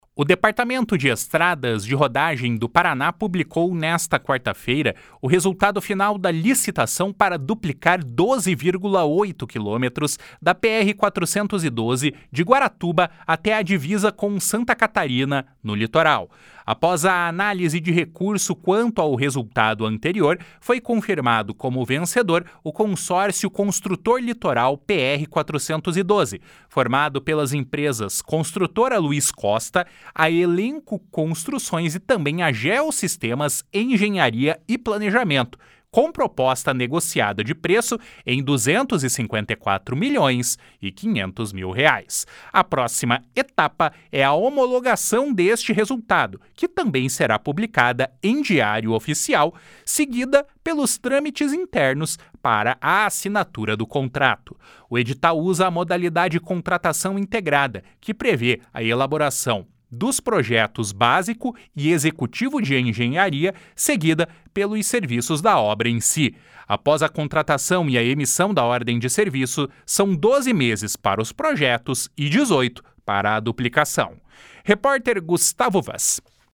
Repórter: